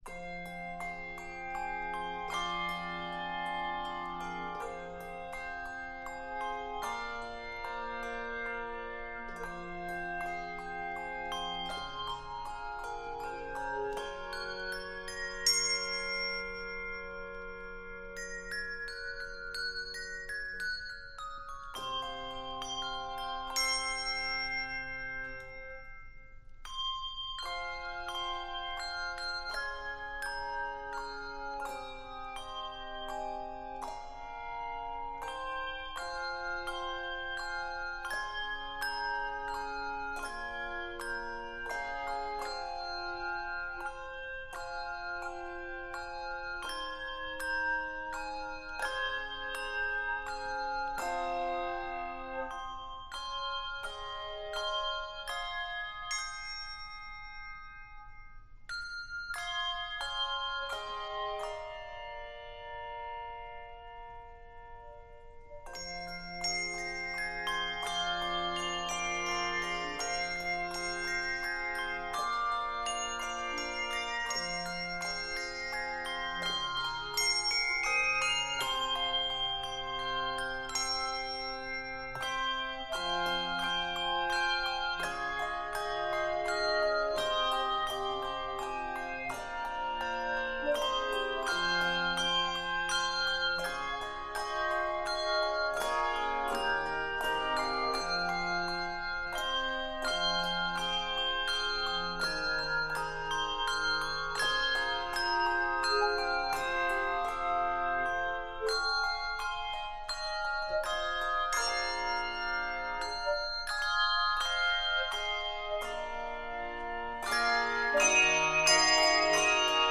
Keys of f minor and eb minor.